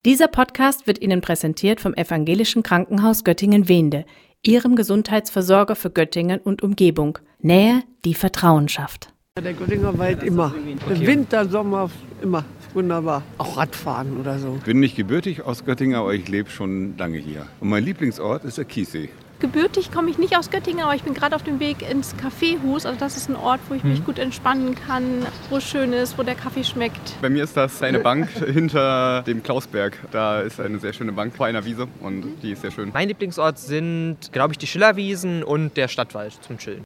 Sendung: Umfragen Redaktion